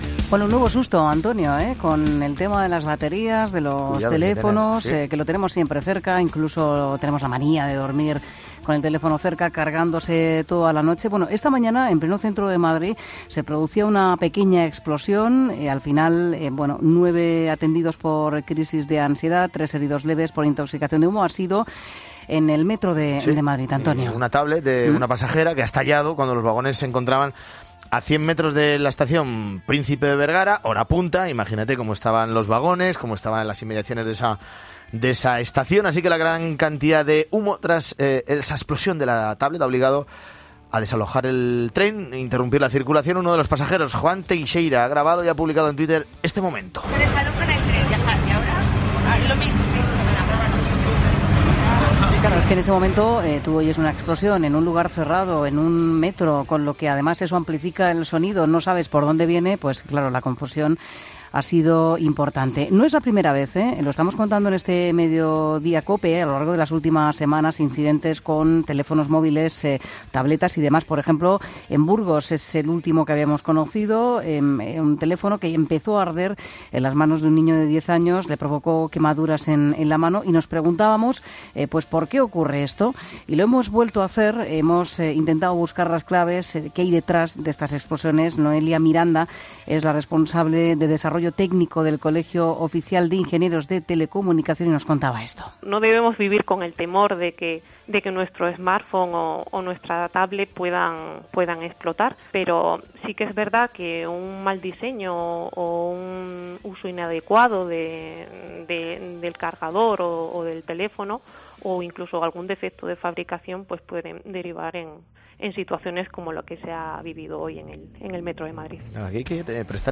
Una experta nos da las claves para evitar este tipo de incidentes